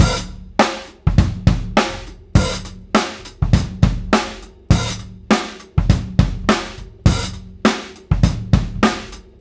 • 102 Bpm Drum Loop Sample G Key.wav
Free drum loop - kick tuned to the G note. Loudest frequency: 1345Hz
102-bpm-drum-loop-sample-g-key-F99.wav